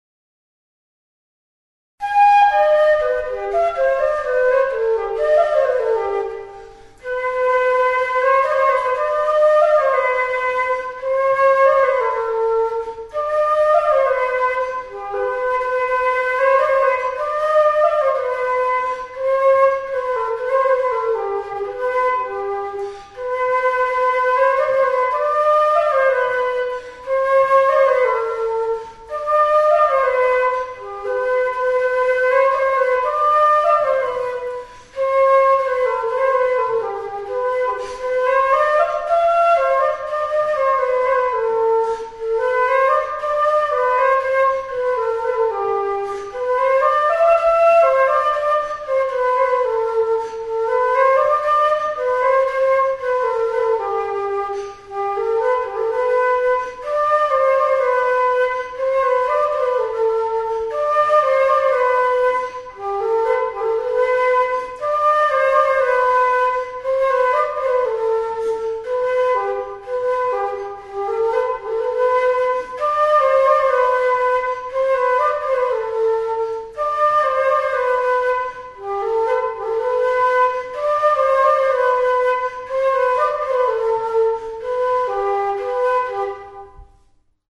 Aerophones -> Flutes -> Transverse flutes
Oiartzun, 2012ko abuztuan.
Recorded with this music instrument.
EUROPE -> GALIZIA
REQUINTA; FLAUTA